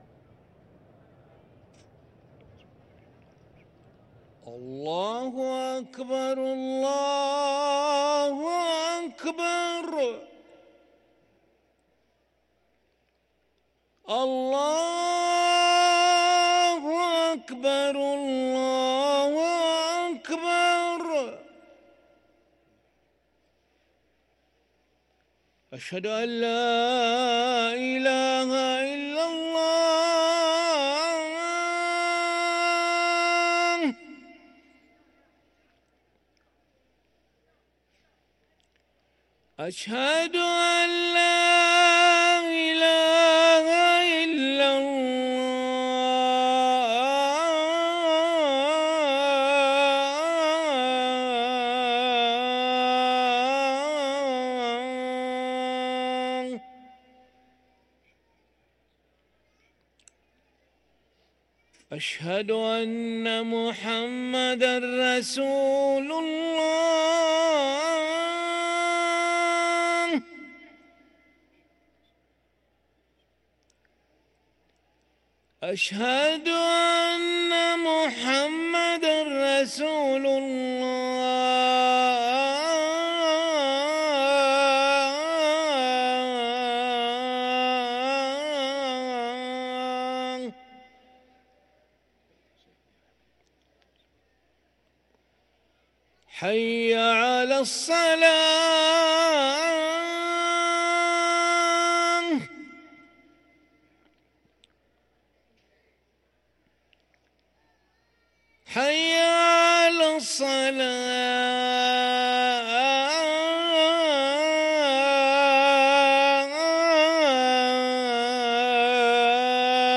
أذان العشاء للمؤذن علي ملا الأحد 20 شعبان 1444هـ > ١٤٤٤ 🕋 > ركن الأذان 🕋 > المزيد - تلاوات الحرمين